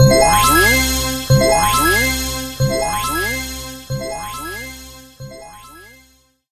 8bit_ulti_02.ogg